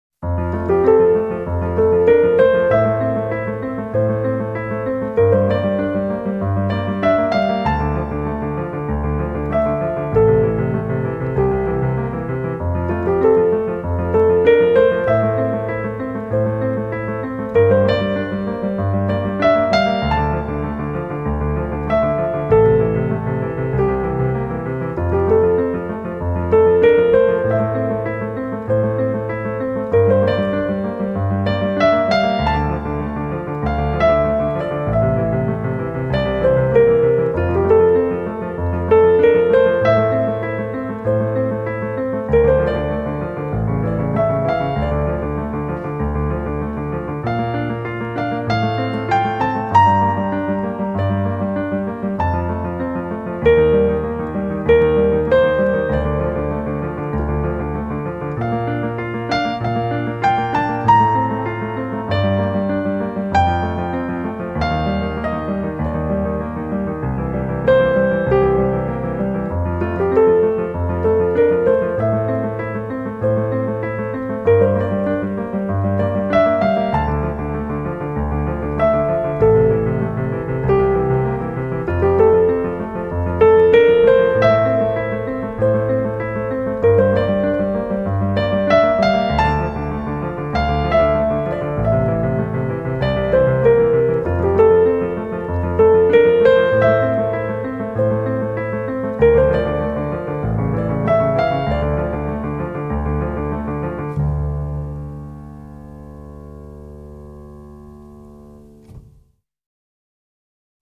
铺陈出轻缓优美的旋律，流泄出法式的浪漫情调.